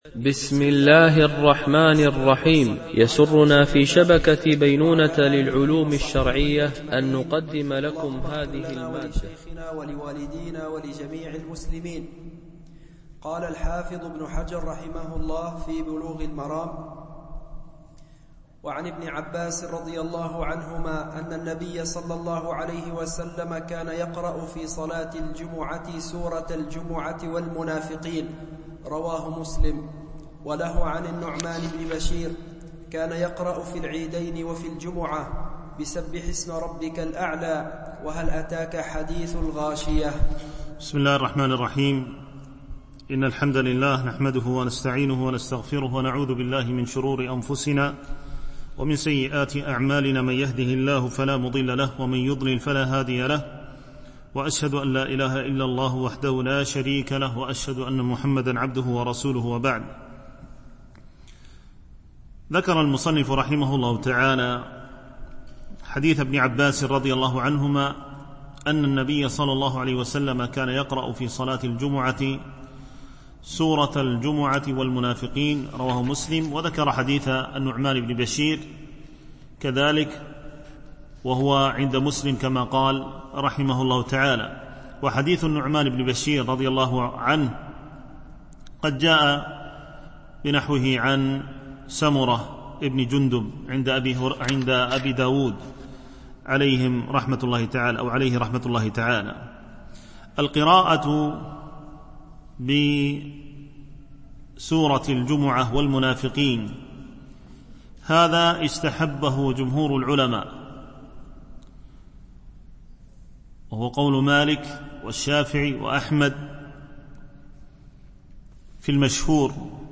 شرح كتاب بلوغ المرام من أدلة الأحكام - الدرس 61 (كتاب الصلاة ، الحديث 436 -445 )
التنسيق: MP3 Mono 22kHz 32Kbps (CBR)